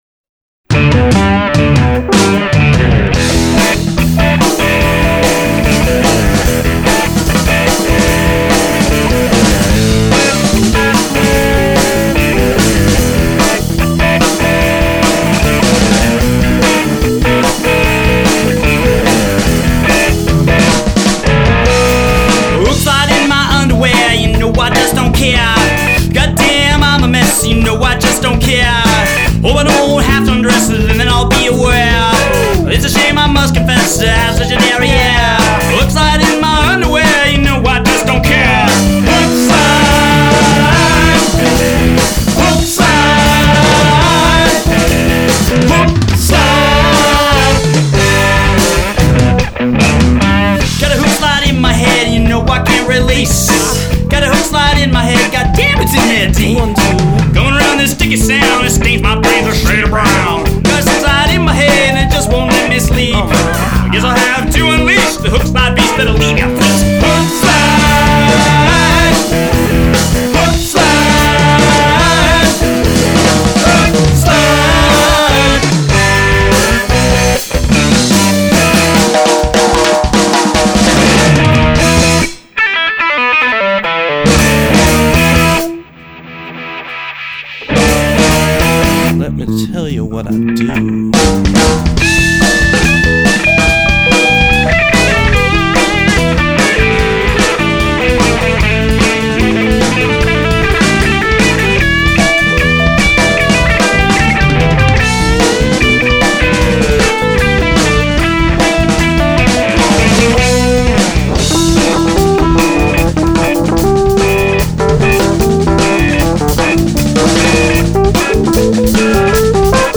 The high energy project is upbeat and animated.